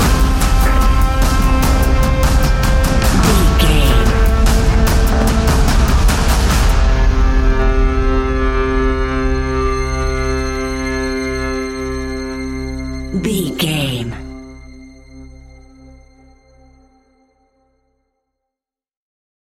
Fast paced
In-crescendo
Ionian/Major
industrial
dark ambient
EBM
synths
Krautrock
instrumentals